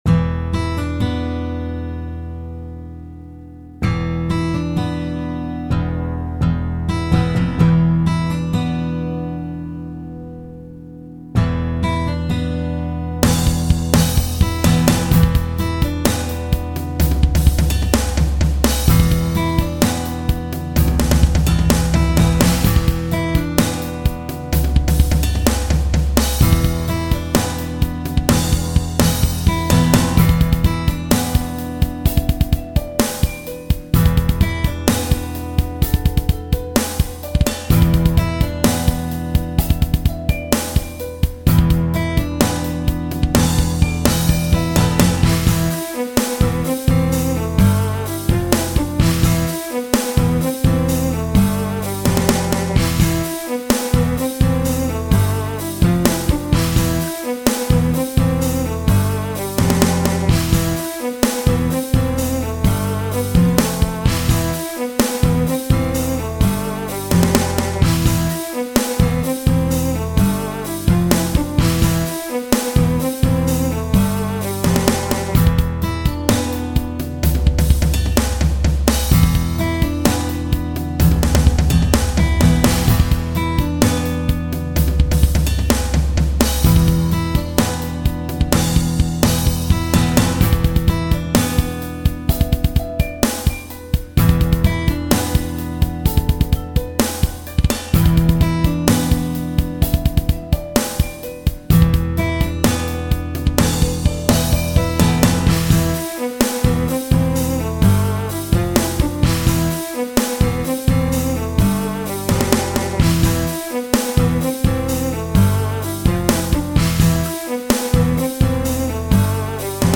i wrote this song a few yers ago , its kind of an alternative/acoustic hard-core-ish feeling.